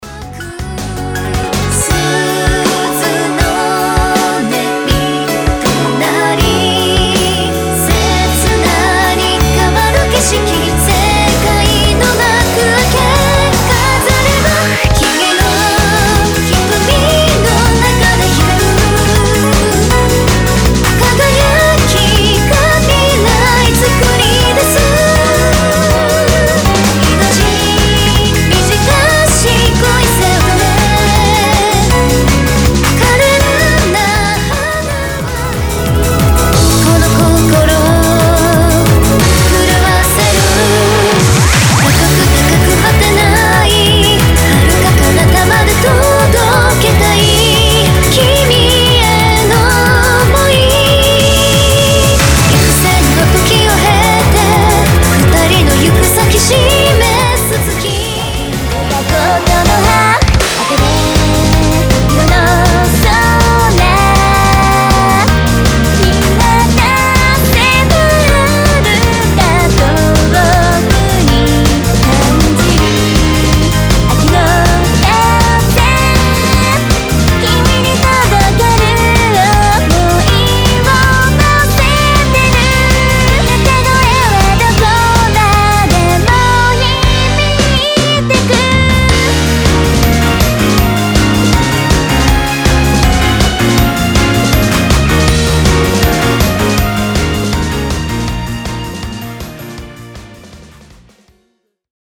EUROBEAT
クロスフェードmp3 　XFD mp3